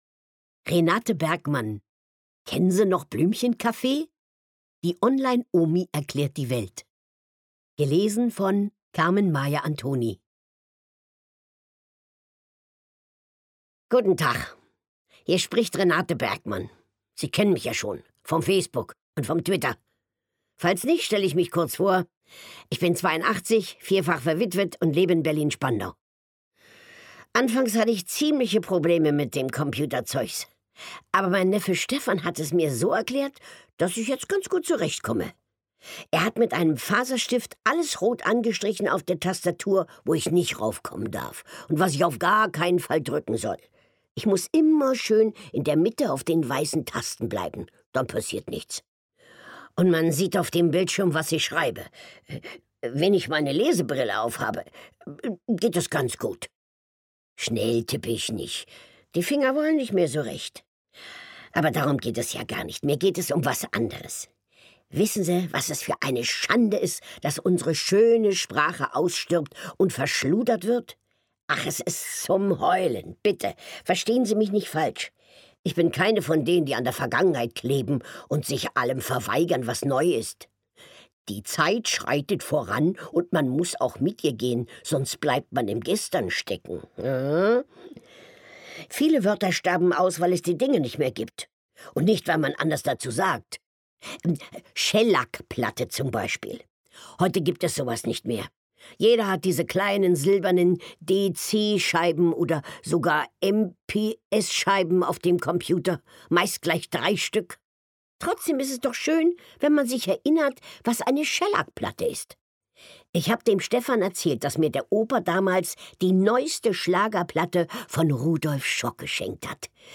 Lesung mit Carmen-Maja Antoni (1 CD)
Carmen-Maja Antoni (Sprecher)